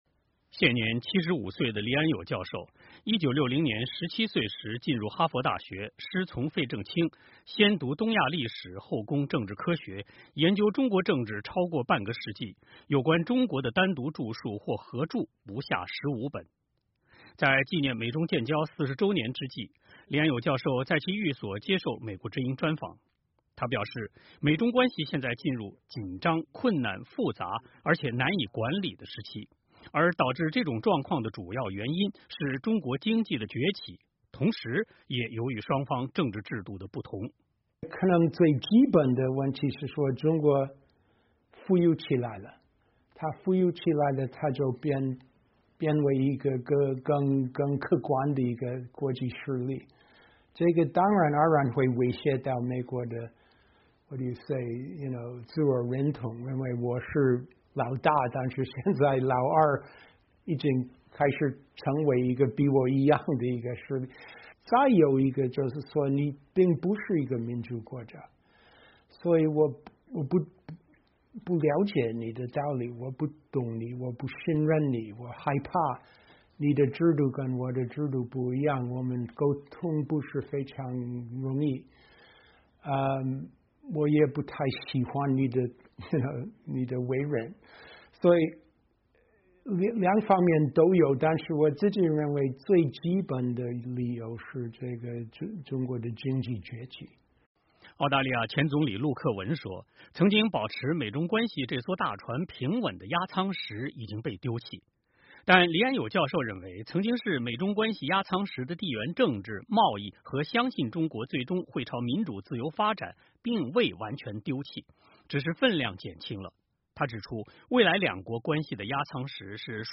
在纪念美中建交40周年之际，黎安友教授在寓所接受美国之音专访。
美国哥伦比亚大学政治学教授黎安友(Andrew Nathan) 接受美国之音专访。